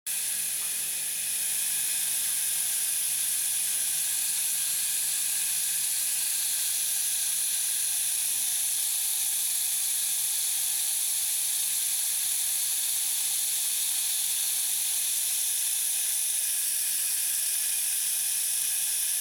جلوه های صوتی
دانلود صدای جلز و ولز روغن در کف ماهیتابه از ساعد نیوز با لینک مستقیم و کیفیت بالا